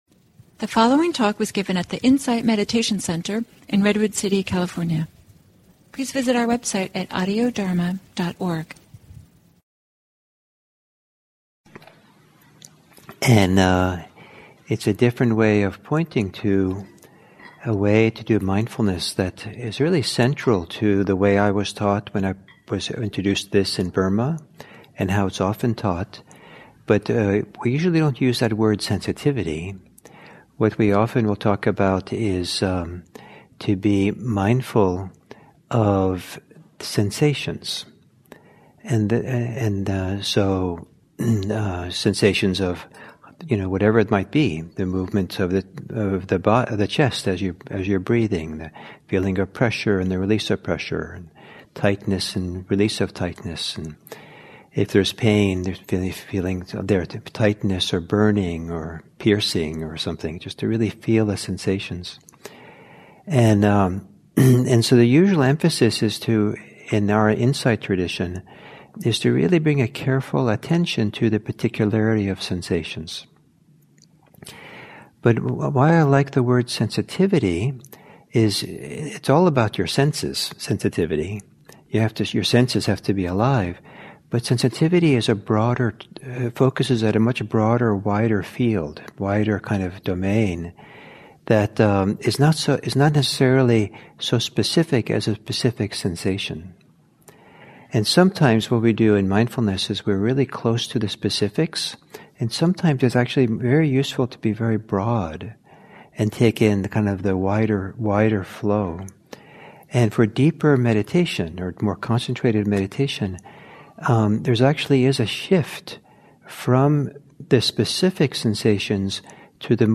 dharma talks
at the Insight Meditation Center in Redwood City, CA.